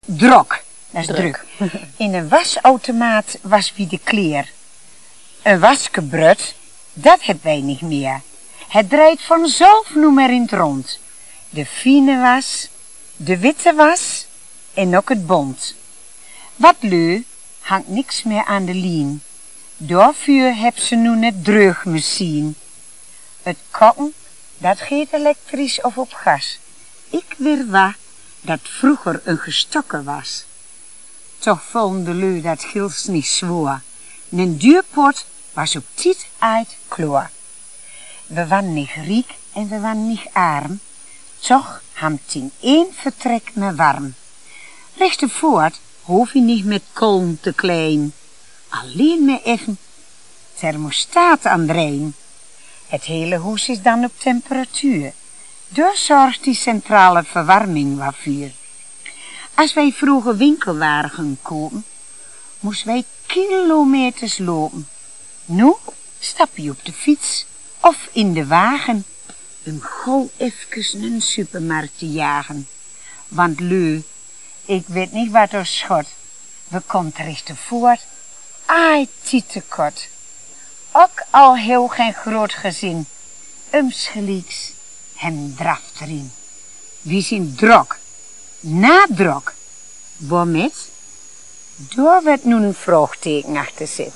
Dit is een Twents gedicht
twents-gedicht.mp3